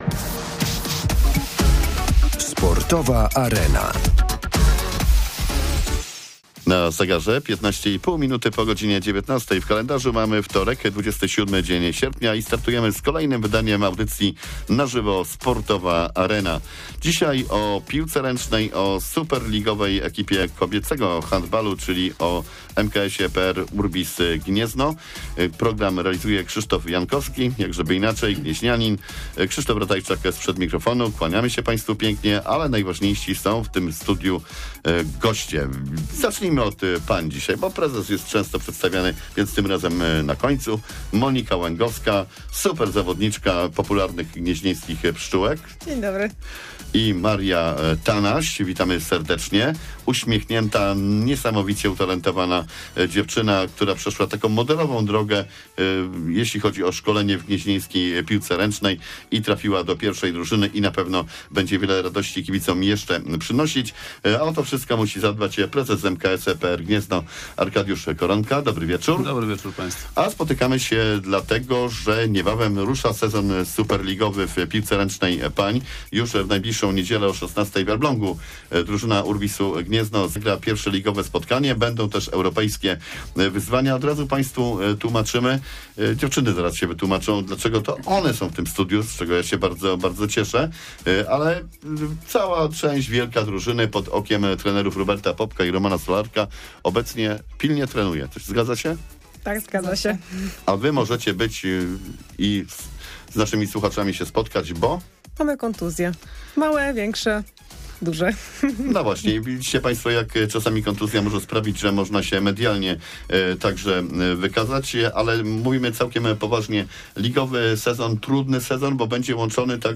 O Superlidze piłkarek ręcznych i ekipie MKS PR URBIS Gniezno, goście na żywo